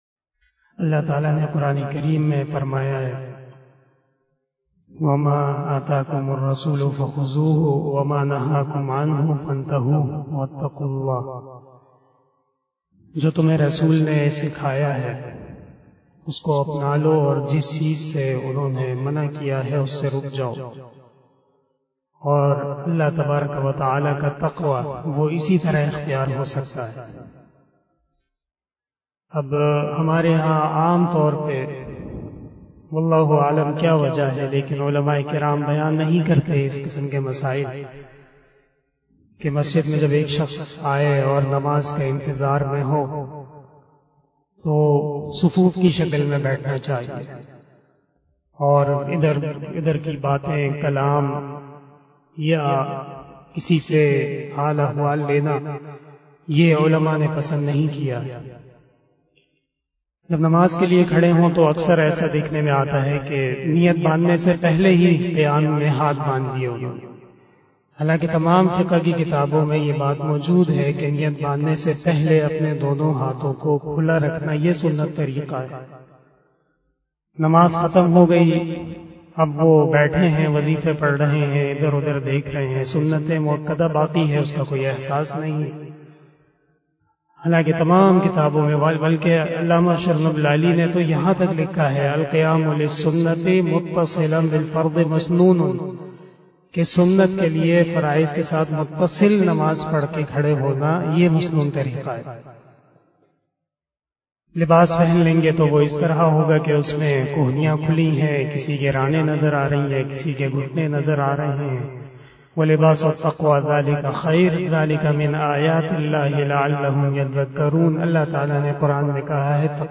039 After asar Namaz Bayan 06 August 2021 (26 Zilhajjah 1442HJ) Friday